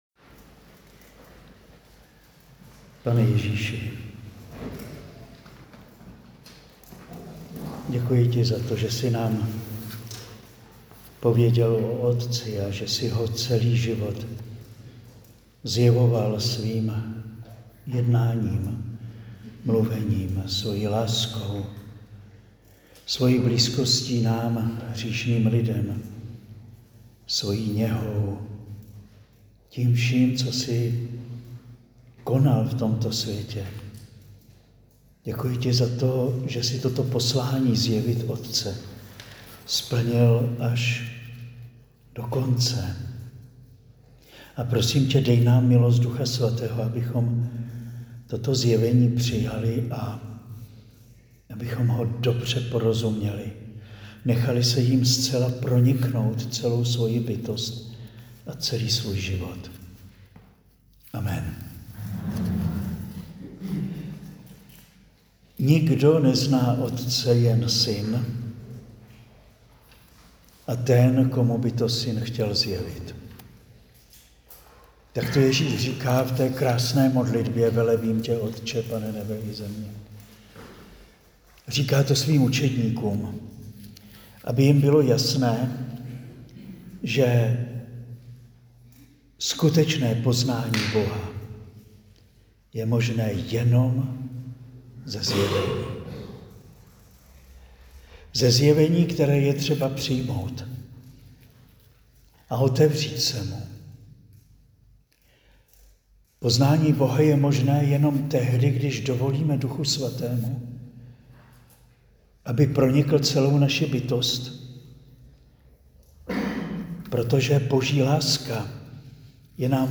Přednáška zazněla na kurzu učednictví v květnu 2025